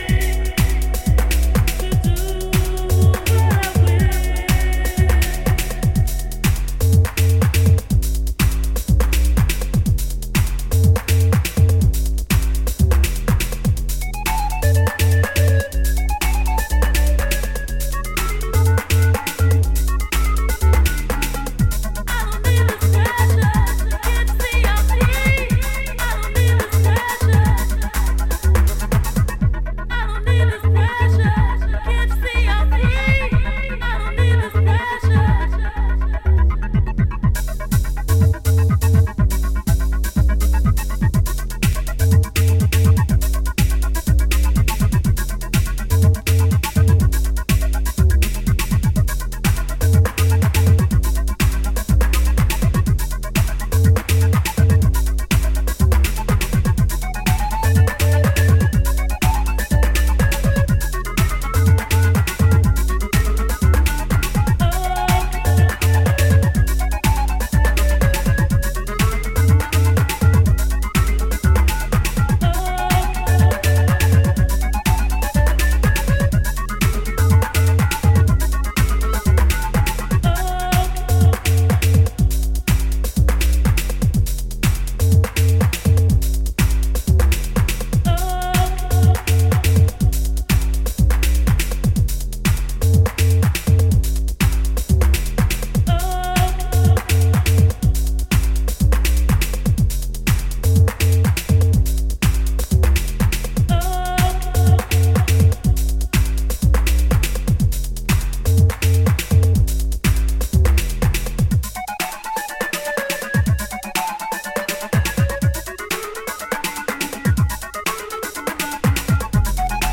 radio mix